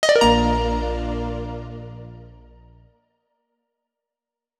Positive Holy1.wav